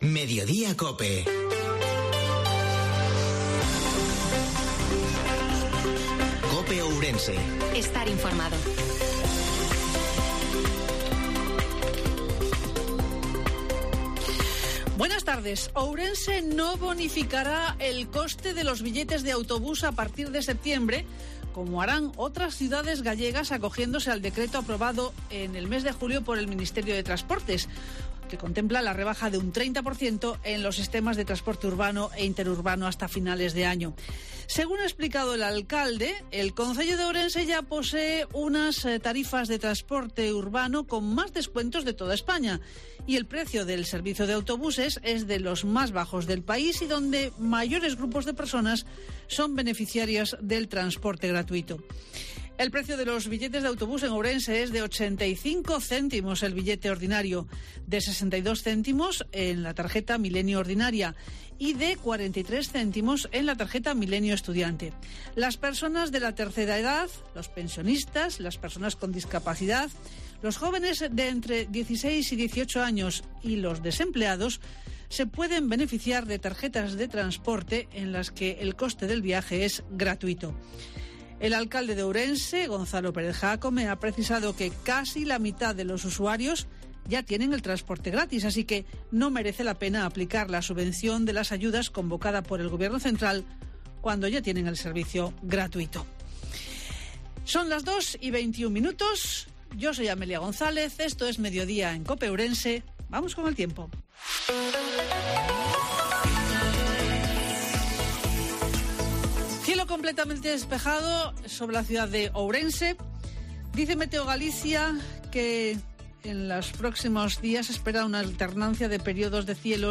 INFORMATIVO MEDIODIA COPE OURENSE-18/08/2022